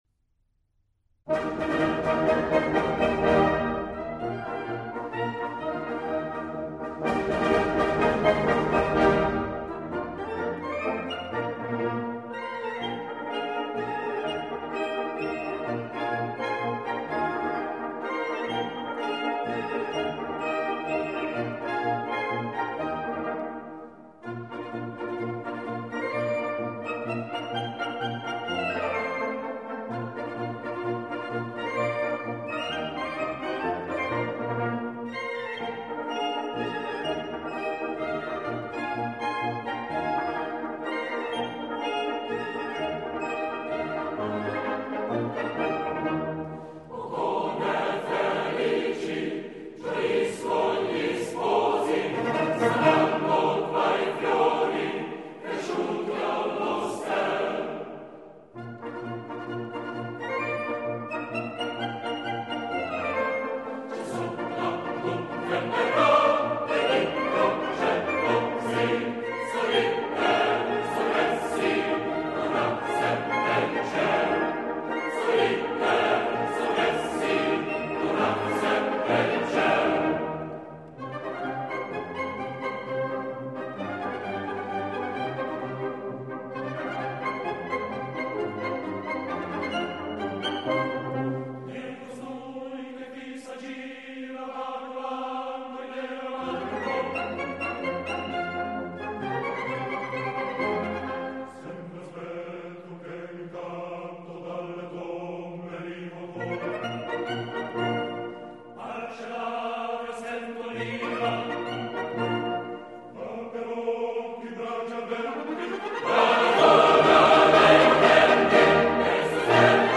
coro